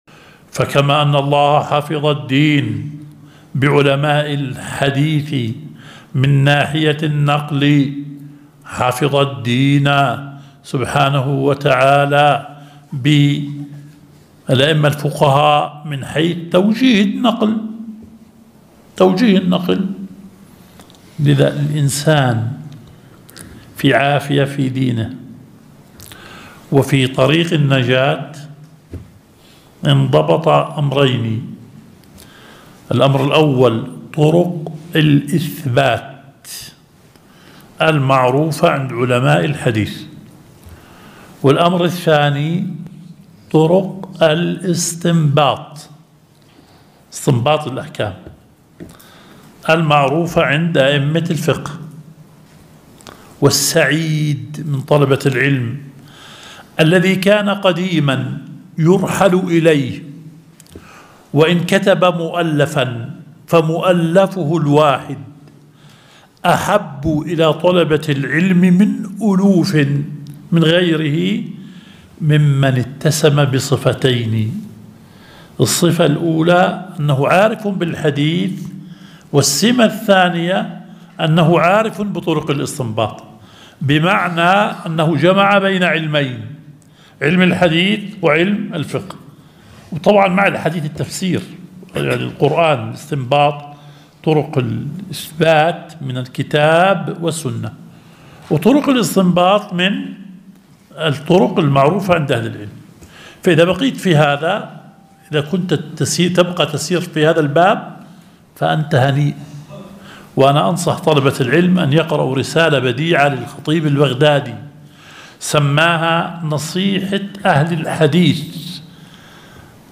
درس ١٤ – مبحث العام والخاص